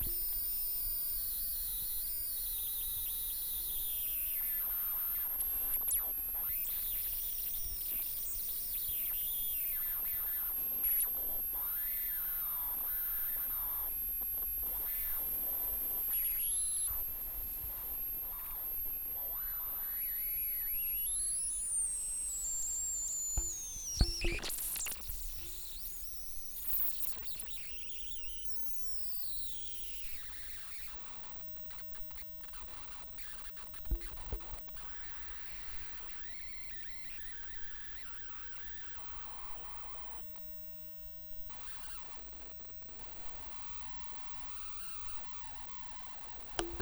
nord-acoustics-circuit-bending.wav